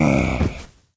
spawners_mobs_mummy_death.2.ogg